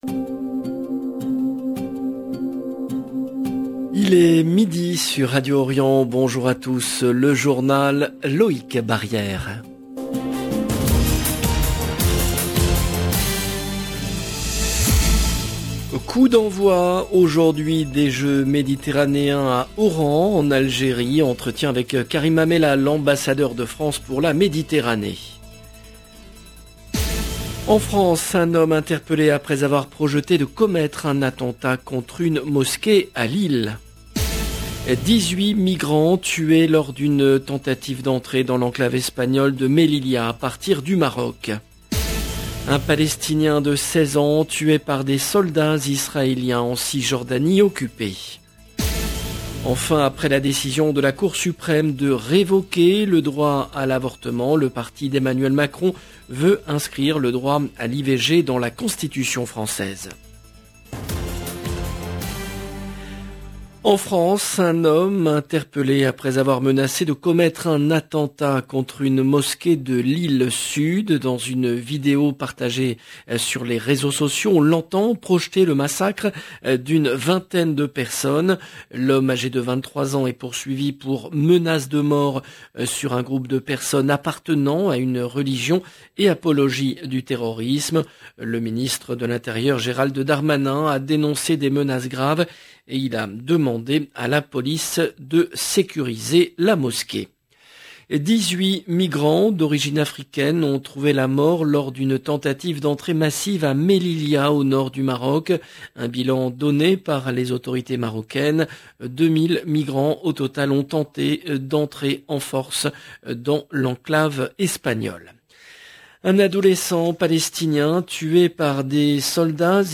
Coup d’envoi aujourd’hui des Jeux Méditerranéens à Oran, en Algérie. Entretien avec Karim Amellal, ambassadeur de France pour la Méditerranée.